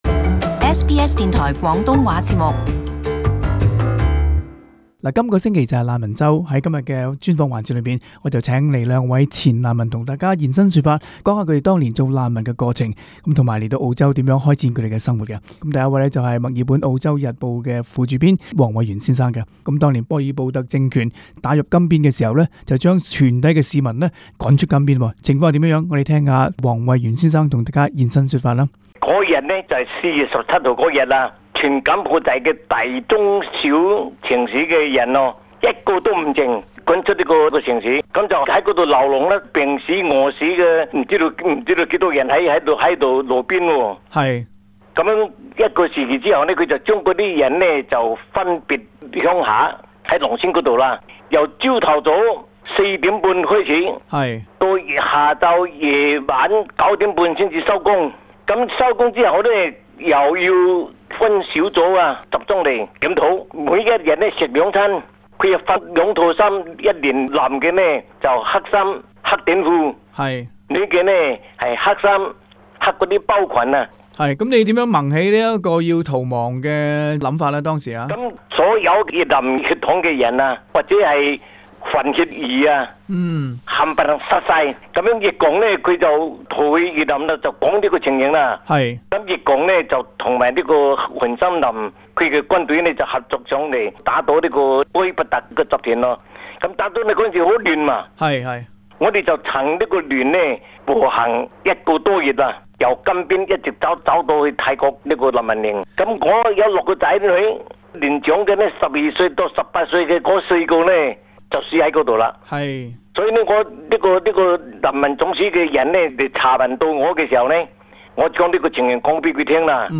【社區專訪】難民週出生入死的體驗